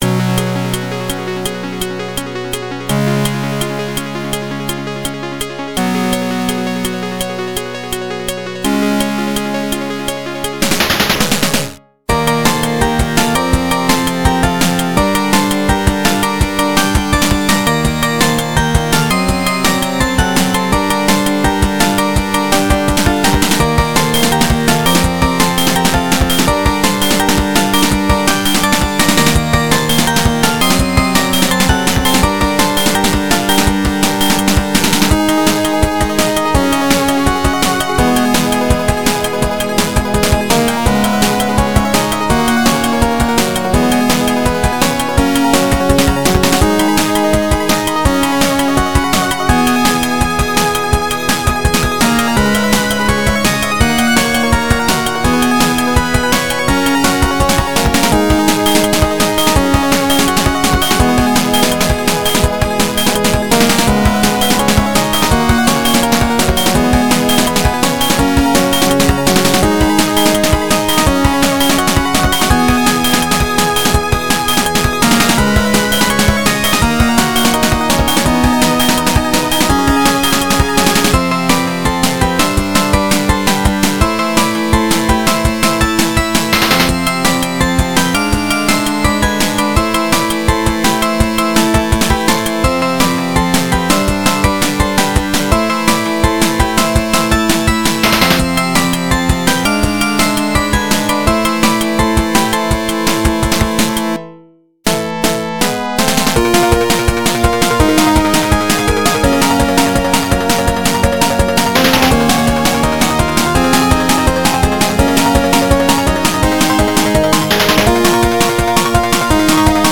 原游戏FM86版，由PMDPlay导出。